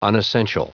Prononciation du mot unessential en anglais (fichier audio)
Prononciation du mot : unessential